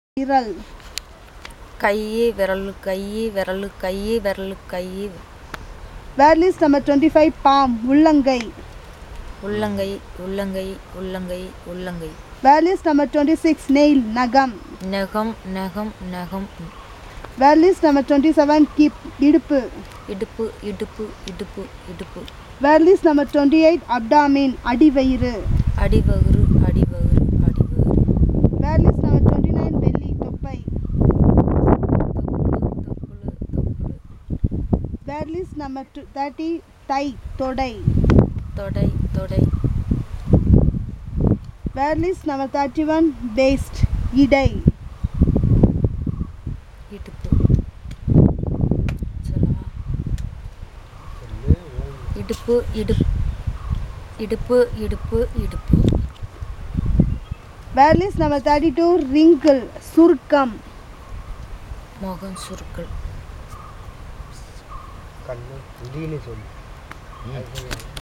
Elicitation of words about human body parts - Part 10